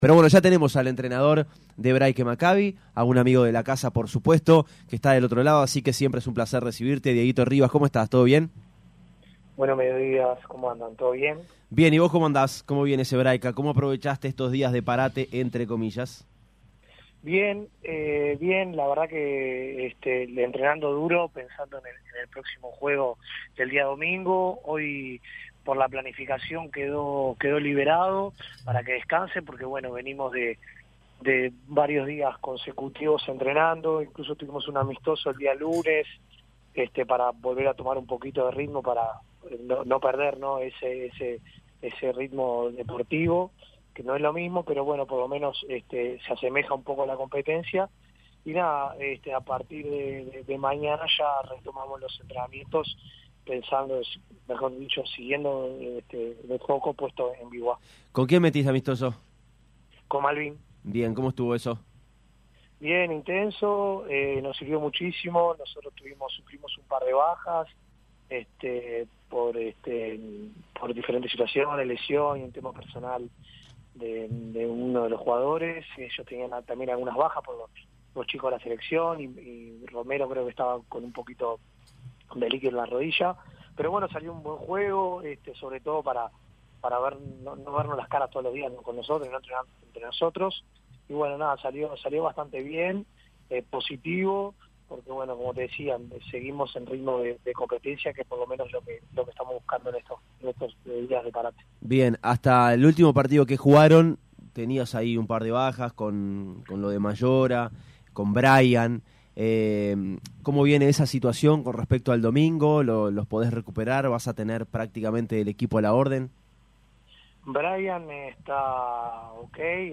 habló con Pica La Naranja previo al retorno de la Liga Uruguaya.